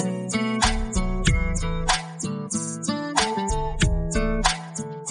pop_sample.mp3